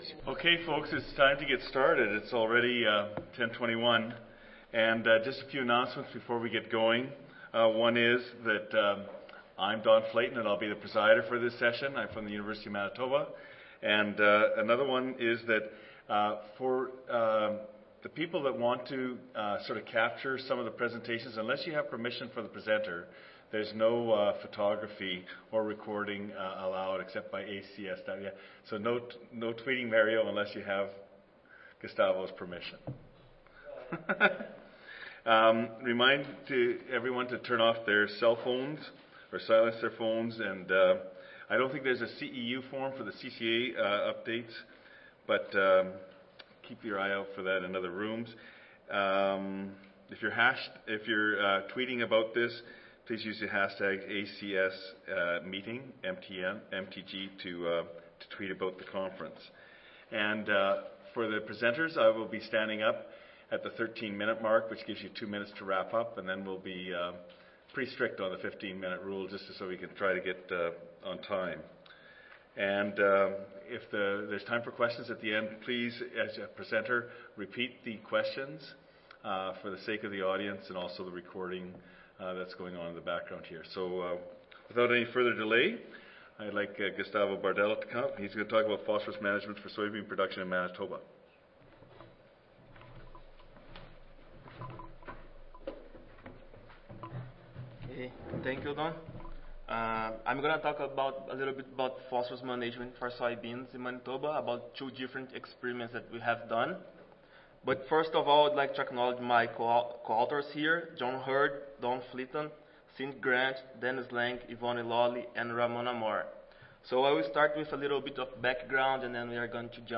Session: M.S. Graduate Student Oral Competition (ASA, CSSA and SSSA International Annual Meetings (2015))
Agriculture and Agri-Food Canada Audio File Recorded Presentation